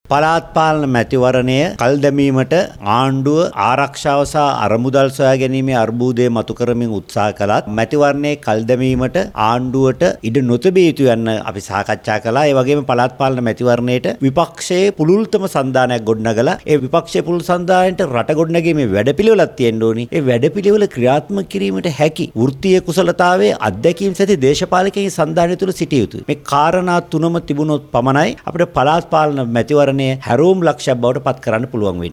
උත්තර ලංකා සභාගයේ සතිපතා පැවැත්වෙන සාකච්ඡාවෙන් අනතුරුව මාධ්‍ය වෙත අදහස් දක්වමින් ඔහු මේ බව සඳහන් කළා.